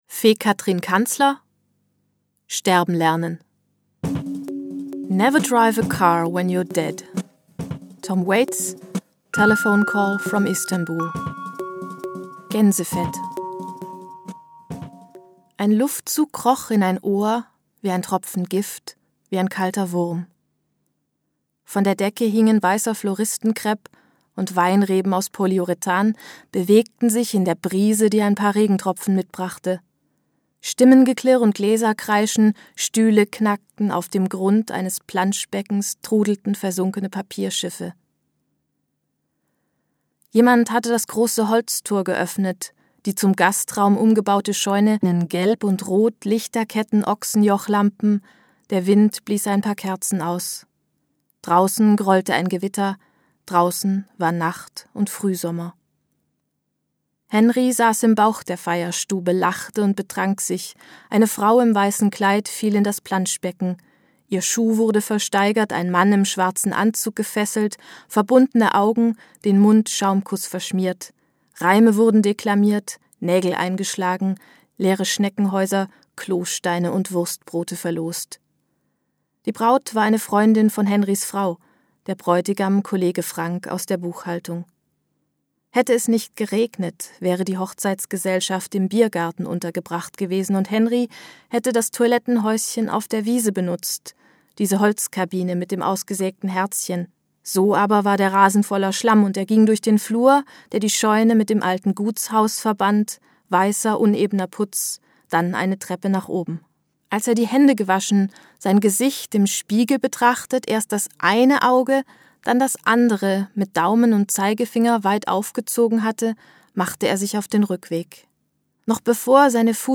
2017 | Hörbuch ungekürzt 5 CDs